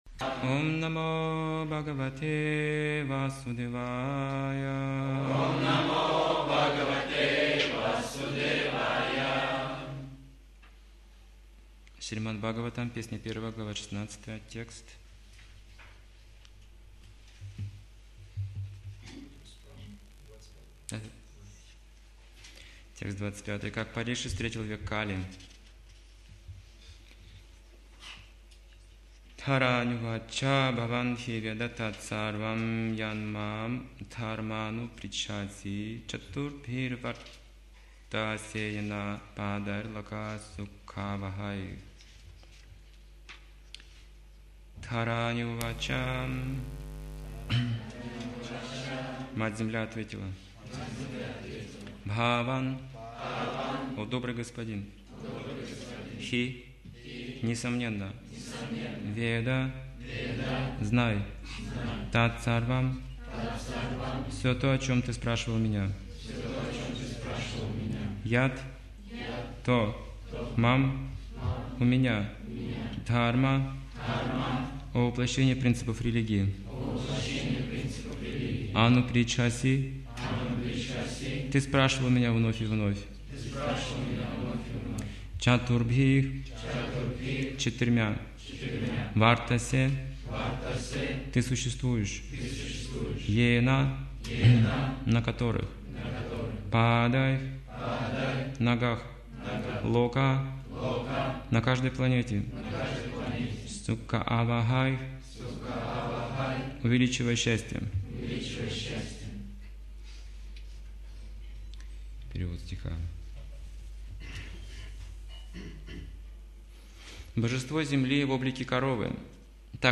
Темы, затронутые в лекции: Корова как олицетворённое божество Земли. Принципы религии - 4 регулирующих принципа.